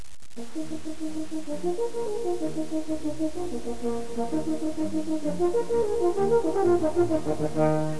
Click here to hear a sample of his Horn Playing Click here to go back to the Home Page